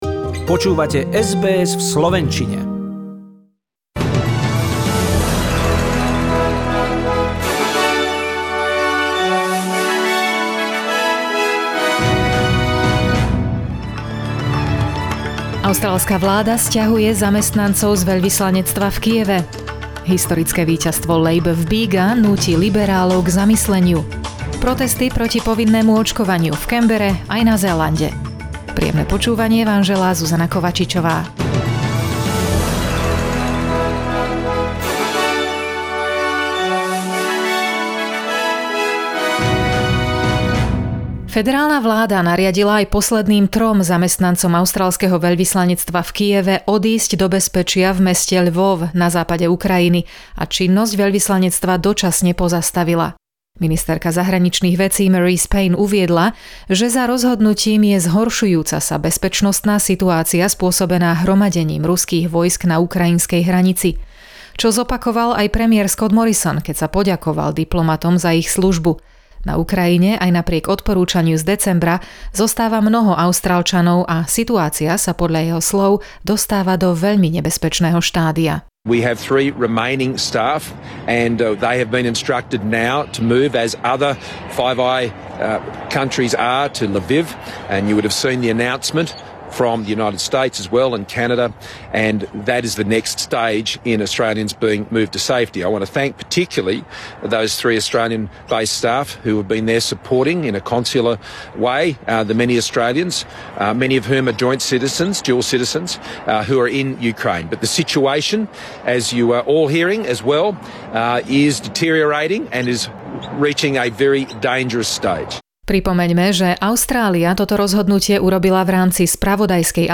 SBS NEWS - správy v slovenčine k 13.februáru 2022